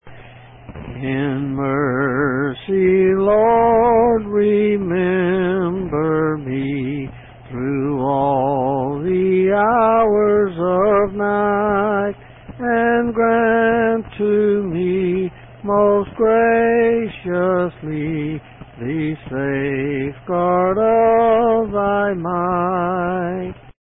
In Mercy Lord Night C. M Click to hear tune